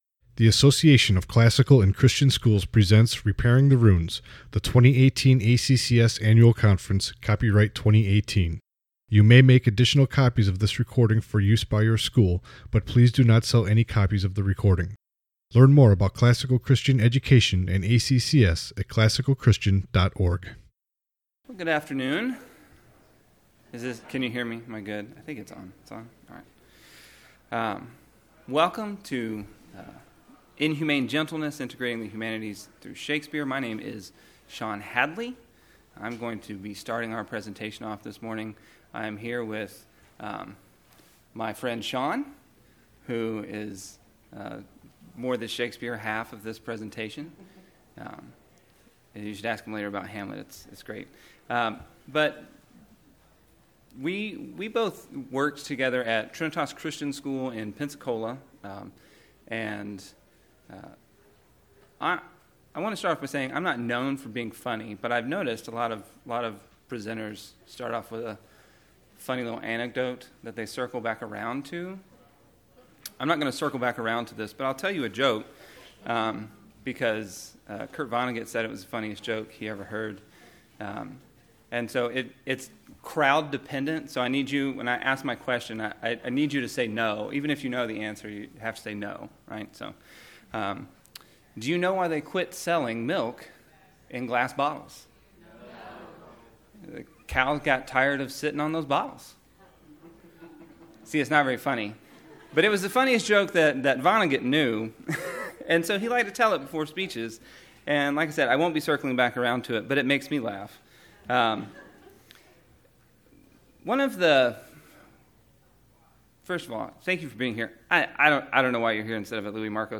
2018 Workshop Talk | 1:03:39 | All Grade Levels, History, Literature